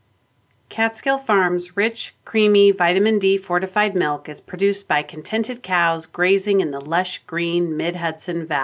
The signal on that is way too low.